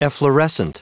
Prononciation du mot efflorescent en anglais (fichier audio)
Prononciation du mot : efflorescent